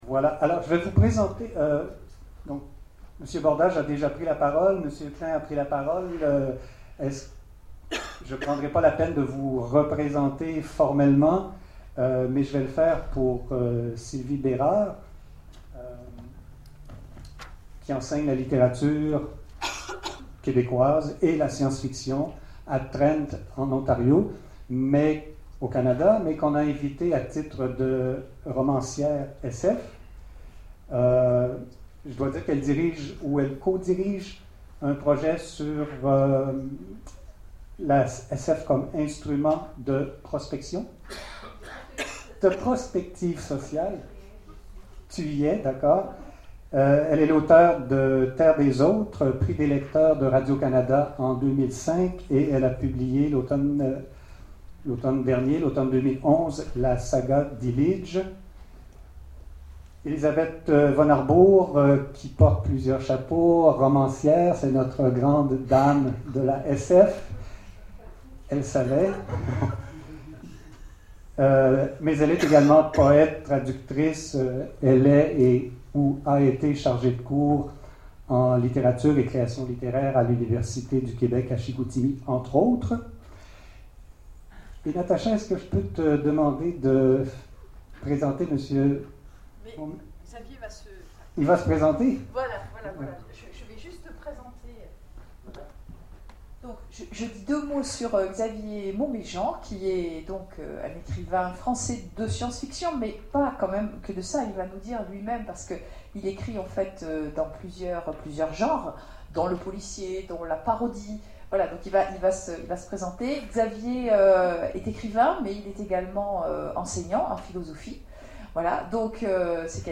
Colloque SF francophone : Table ronde Écrire la science-fiction en français
Conférence